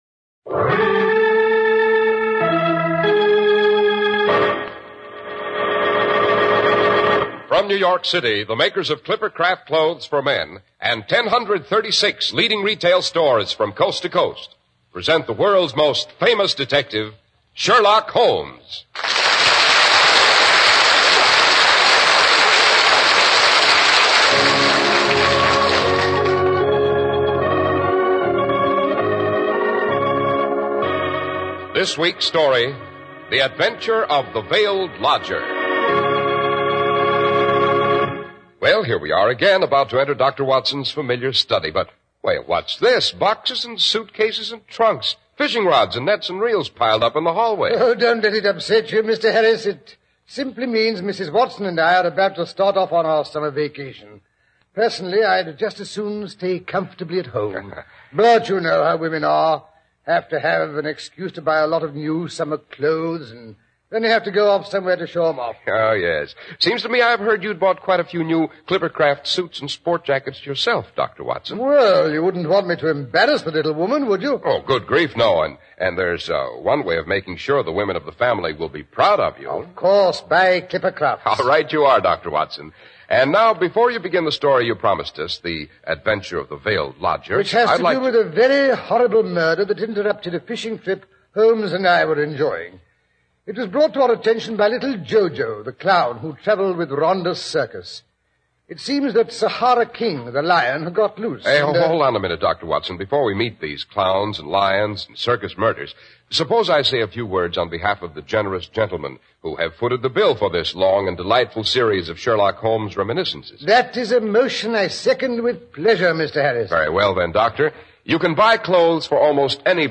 Radio Show Drama with Sherlock Holmes - The Veiled Lodger 1948